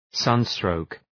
Προφορά
{‘sʌn,strəʋk}